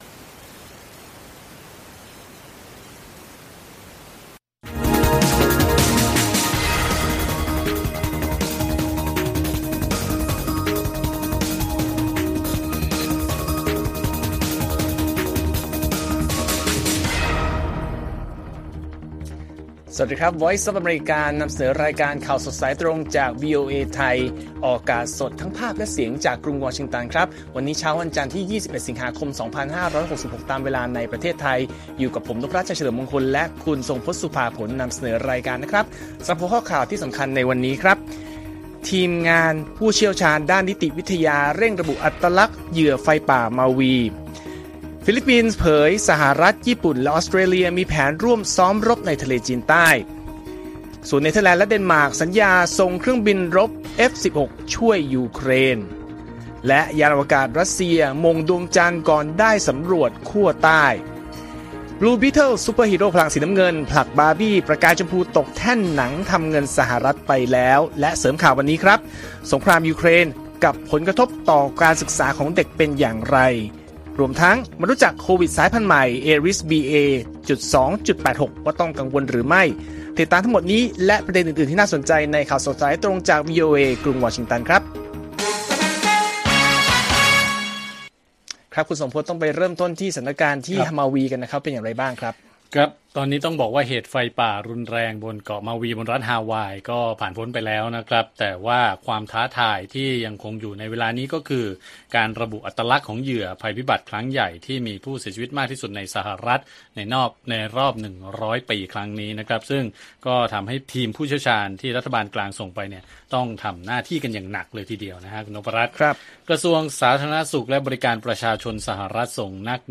ข่าวสดสายตรงจากวีโอเอไทย 6:30 – 7:00 น. วันที่ 21 ส.ค. 2566